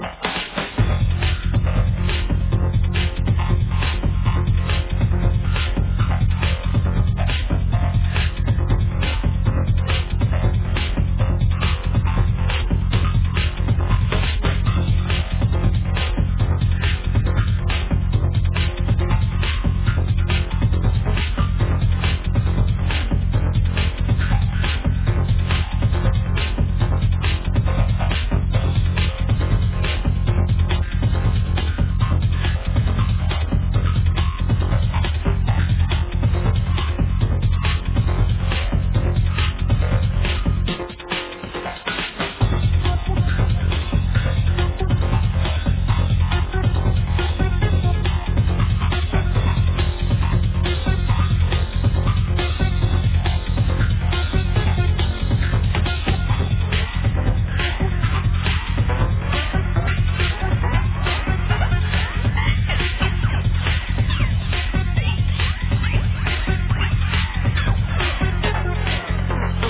need help i.d this breaks tracks.